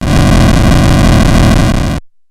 M1_FireLong.wav